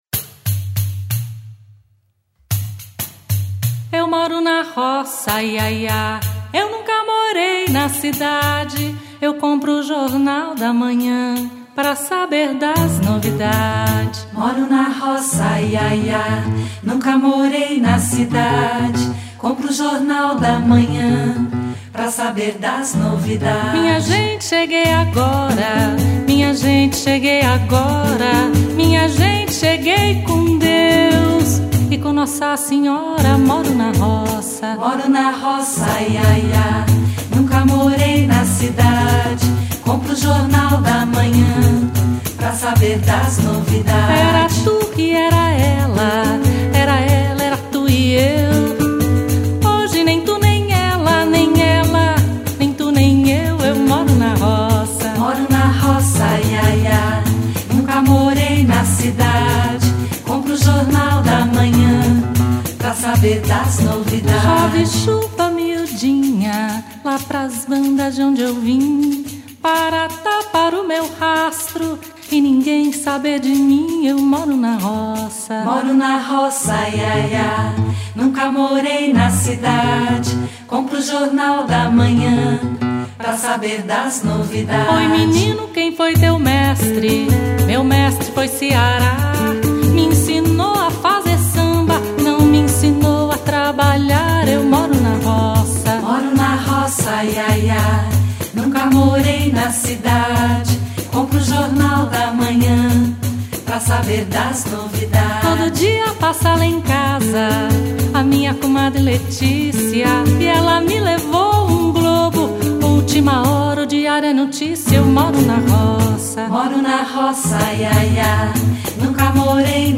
Ouçam outra música pra levantar o astral!!!
samba de domínio público